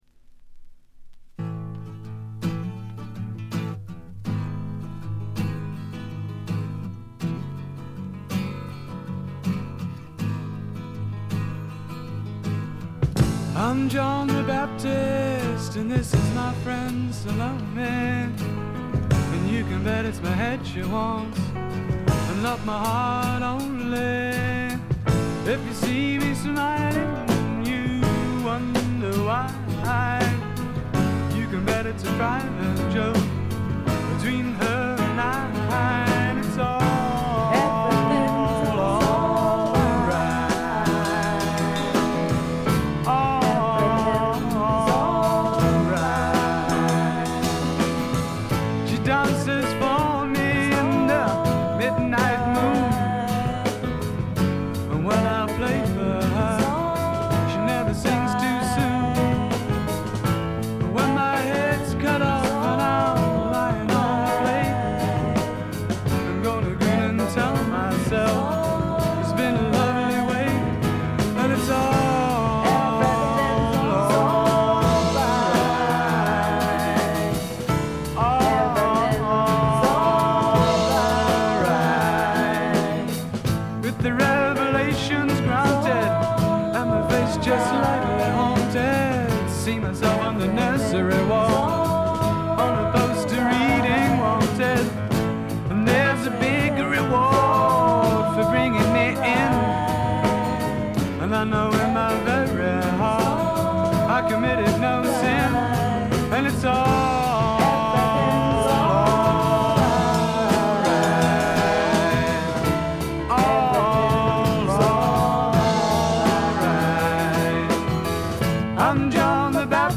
ほとんどノイズ感無し。
音の方はウッドストック・サウンドに英国的な香りが漂ってくるという、この筋の方にはたまらないものに仕上がっています。
試聴曲は現品からの取り込み音源です。